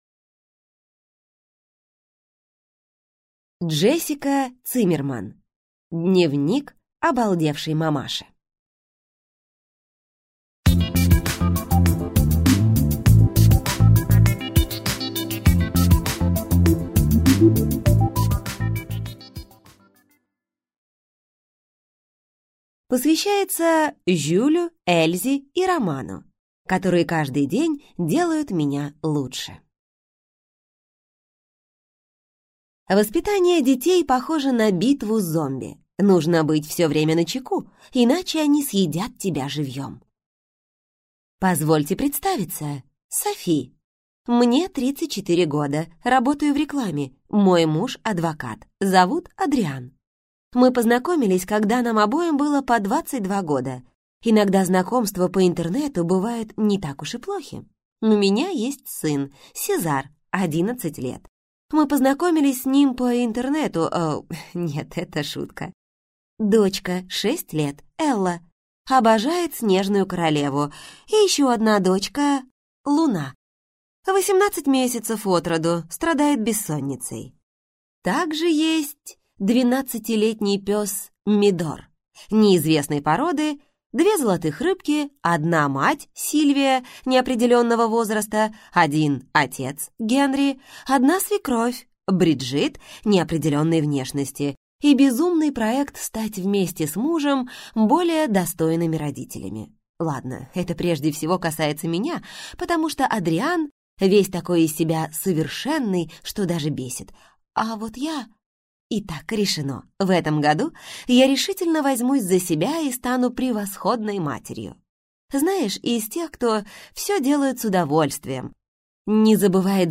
Аудиокнига Дневник обалдевшей мамаши | Библиотека аудиокниг